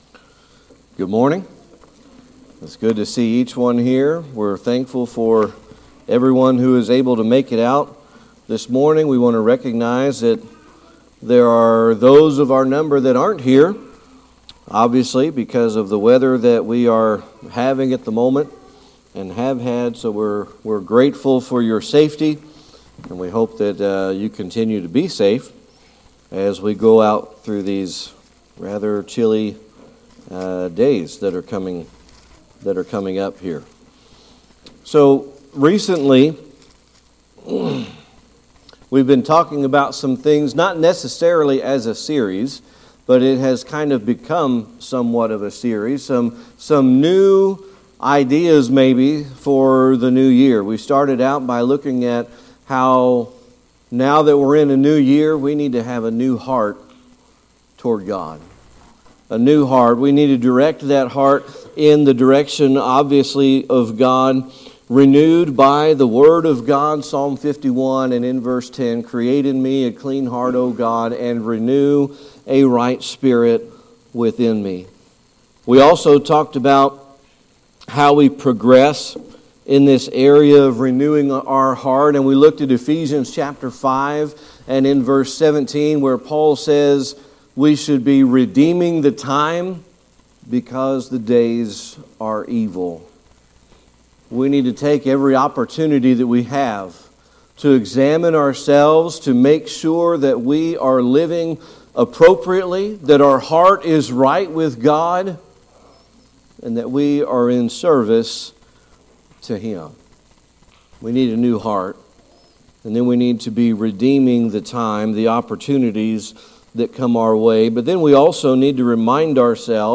Service Type: Sunday Evening Worship